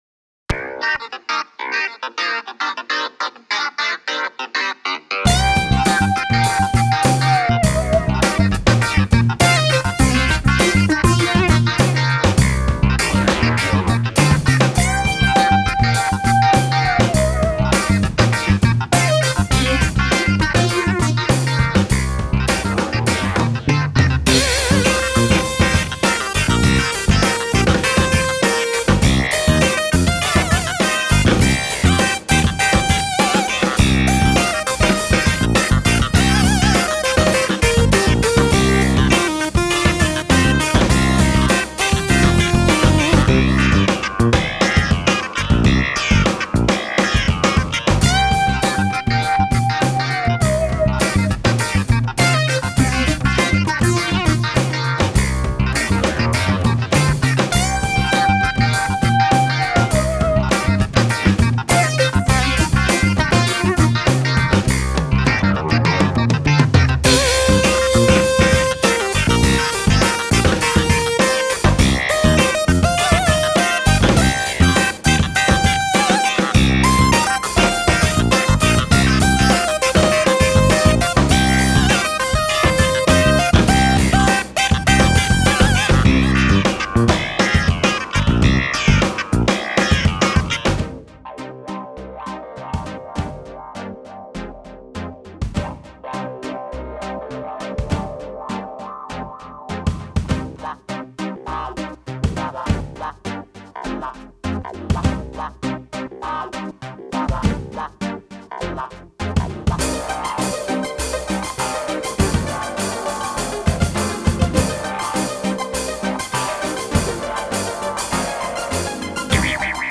Funk - very fun and upbeat